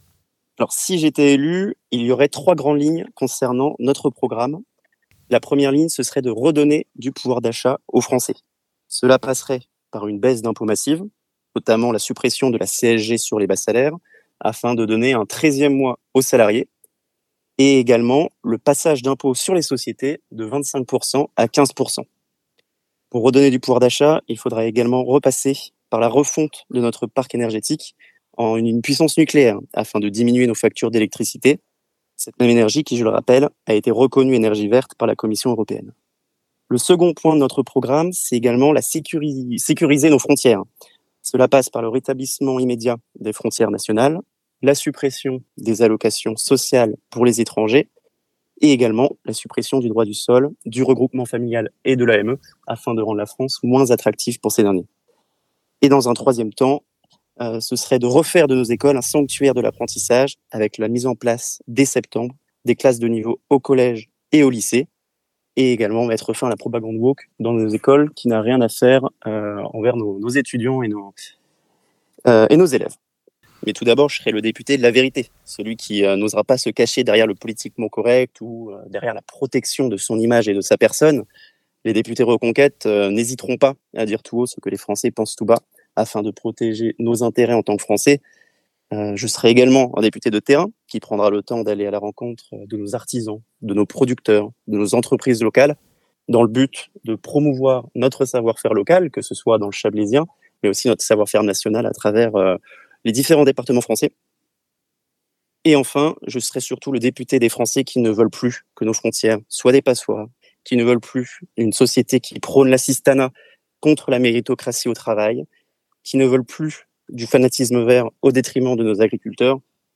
Gros plan sur les candidats du Chablais (interviews)
Voici les interviews des 8 candidats de cette 5ème circonscription de Haute-Savoie (par ordre du tirage officiel de la Préfecture)